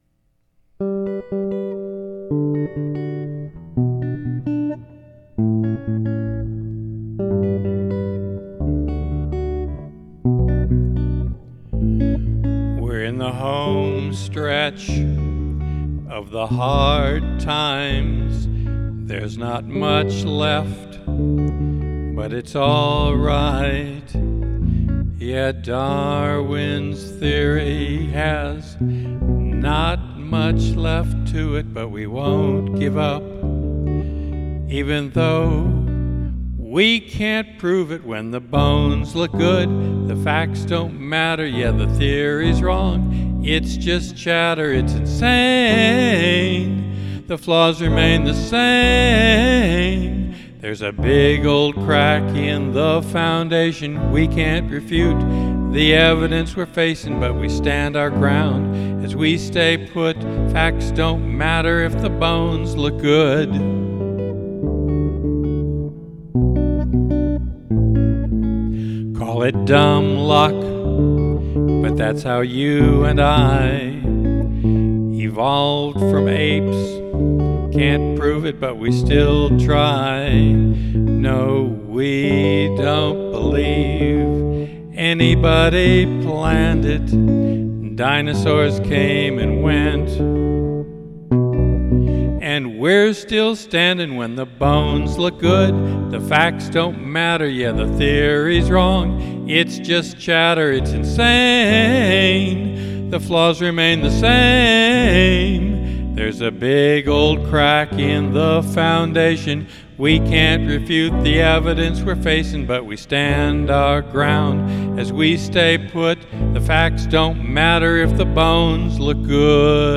Song Parody - April 2021